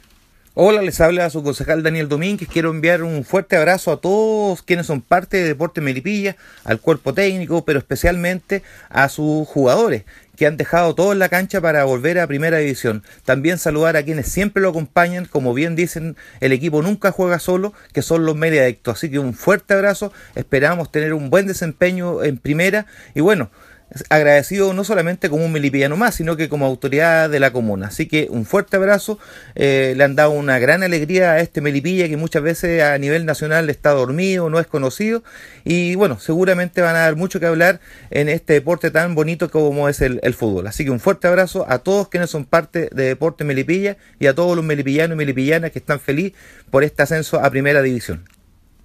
Por su parte, autoridades y candidatos para las elecciones de abril próximo quisieron entregar su saludo al programa radial Entre Portales:
Daniel Dominguez, concejal de Melipilla por la Democracia Cristiana y va a la reelección